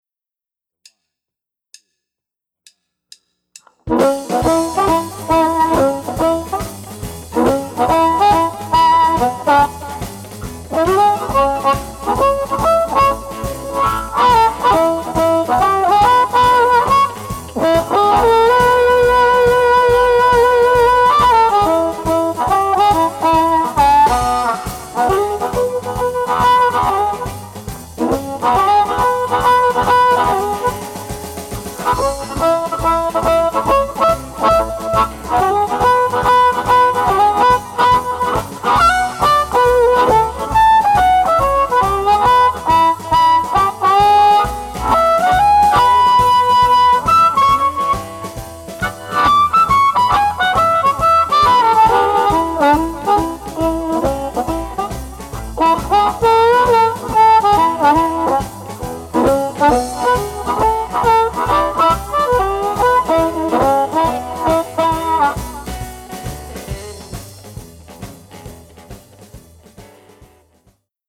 Amp Reviews | Blues Harmonica
Download the review sheet for each amp and the recorded mp3 files to compare the amps to make your choice of favorite amp.